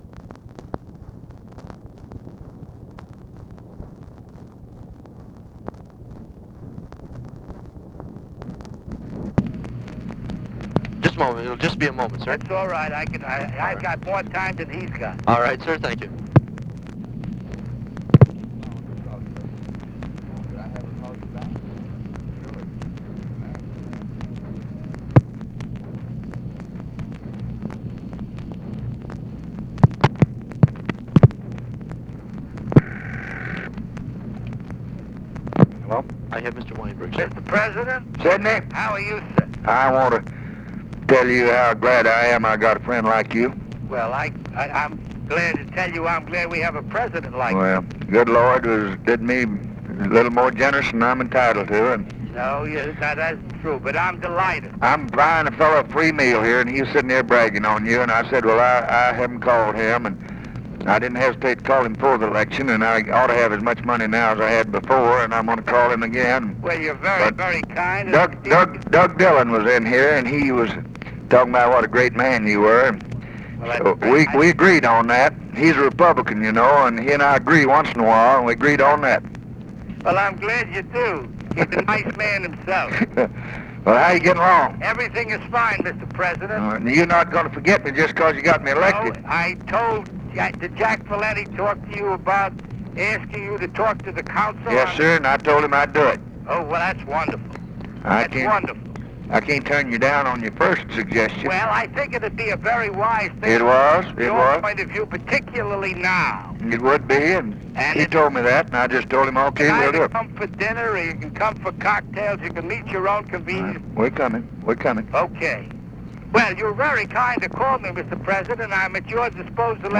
Conversation with SIDNEY WEINBERG and DOUGLAS DILLON, November 11, 1964
Secret White House Tapes